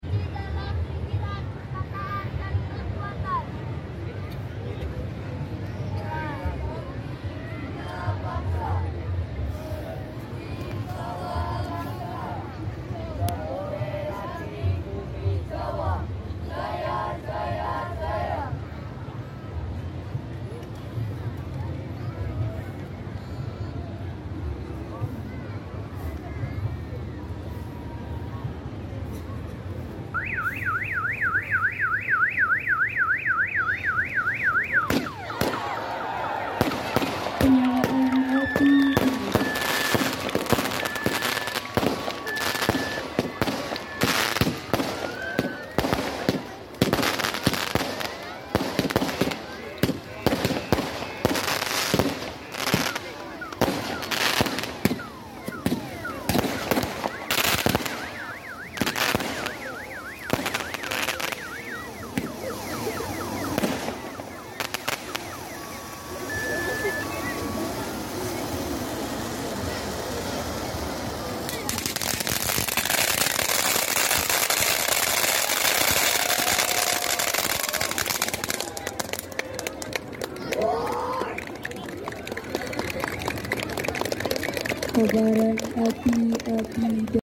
Penyalaan API UNGGUN Perkemahan Penggalang sound effects free download